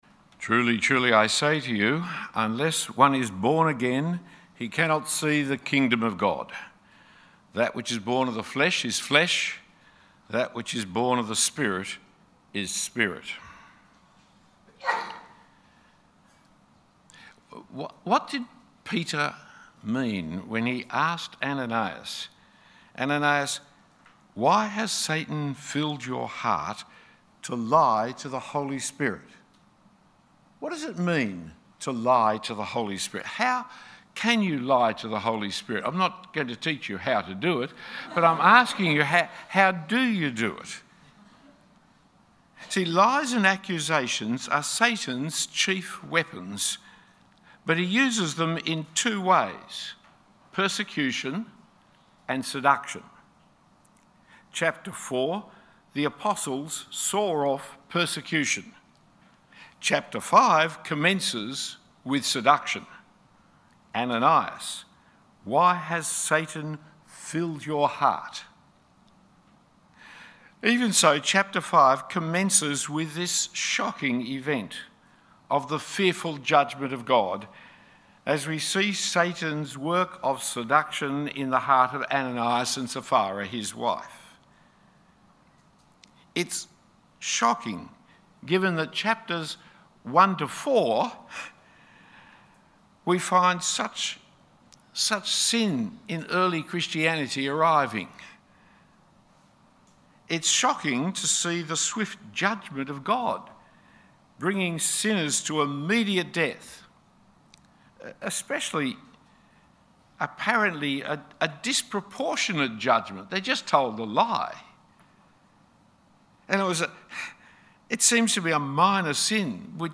A talk given at Moore Theological College chapel.